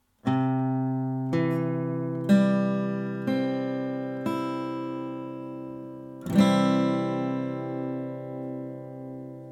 C-Dur (Offen)
C-Dur-Akkord, Gitarre
Hier solltest du versuchen, die E-Saite mit deinem Daumen abzudämpfen.
C-Dur.mp3